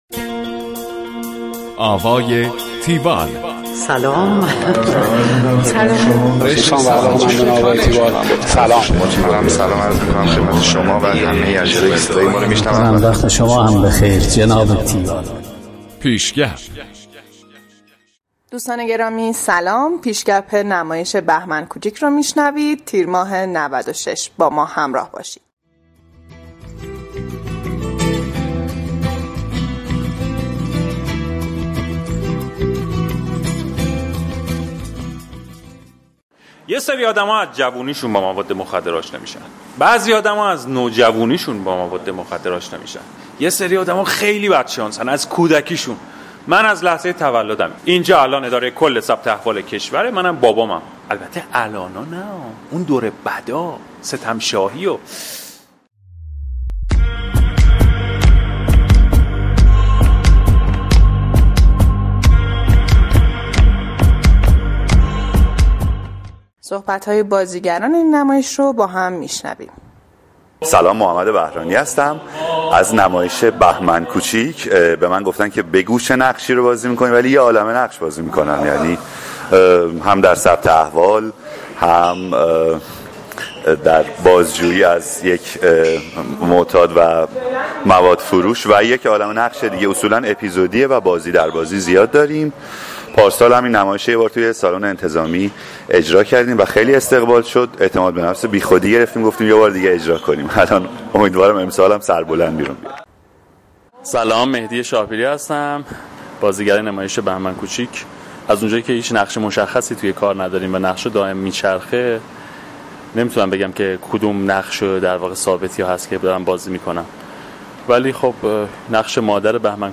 گزارش آوای تیوال از نمایش بهمن کوچیک